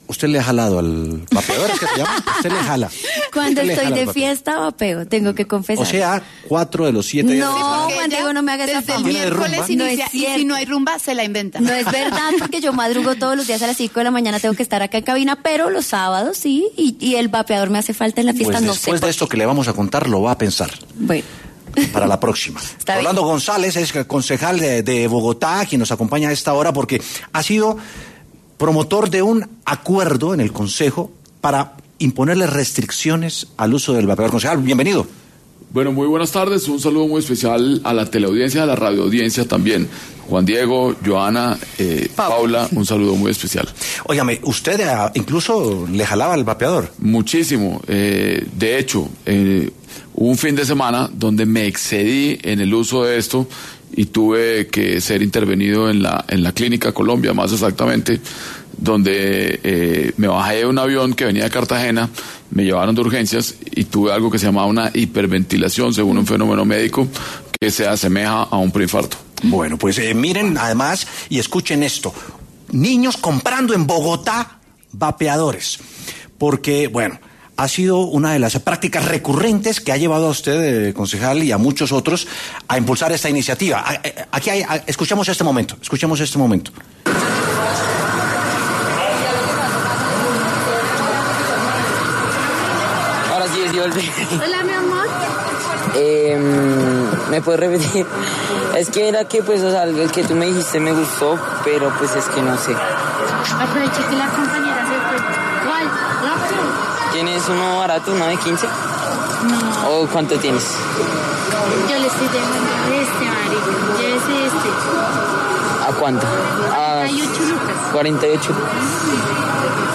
En conversación con W Sin Carreta, el concejal de Bogotá Rolando González, de Cambio Radical, entregó detalles del proyecto que frena el uso de vapeadores en la capital colombiana ante su alarmante aumento en los últimos años.